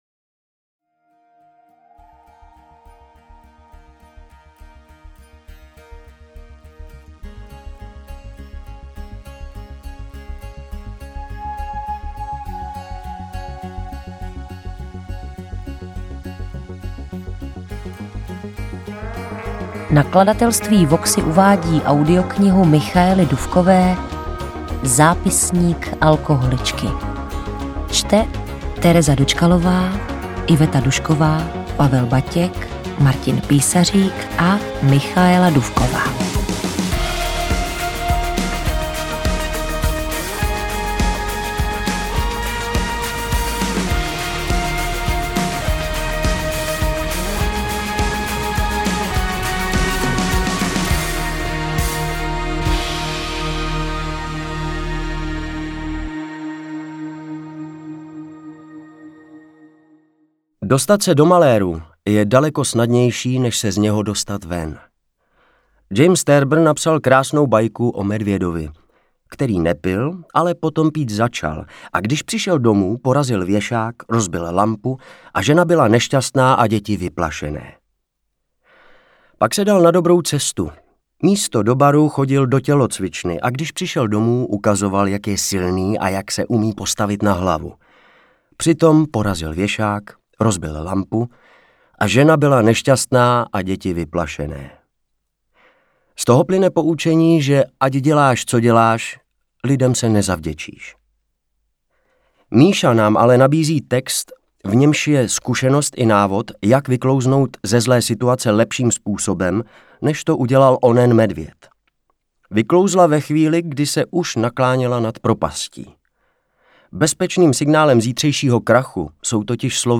AudioKniha ke stažení, 39 x mp3, délka 5 hod. 58 min., velikost 328,1 MB, česky